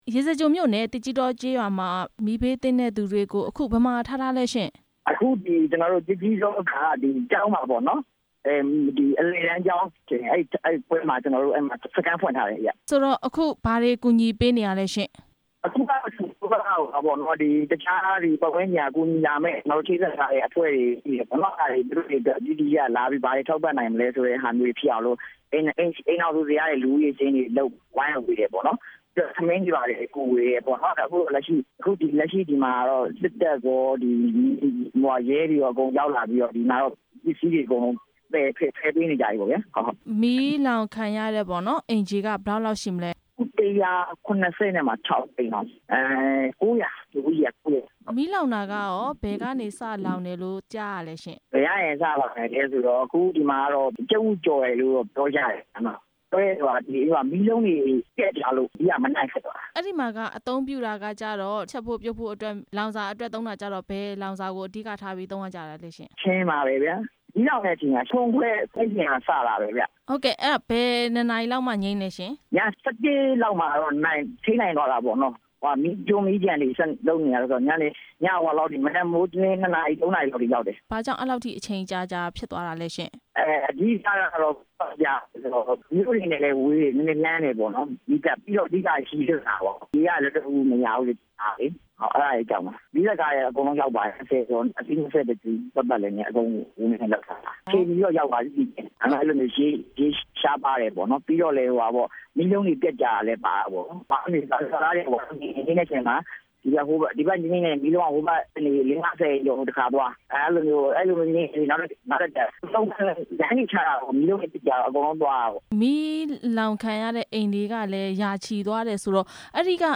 ရေစကြို မီးလောင်မှု မေးမြန်းချက်